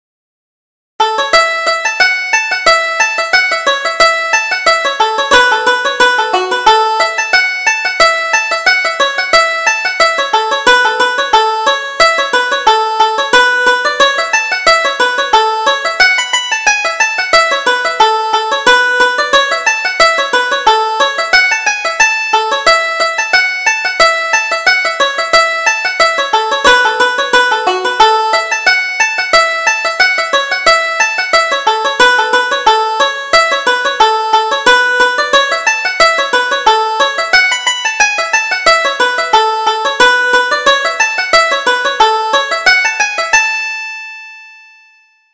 Reel: The Goldfinch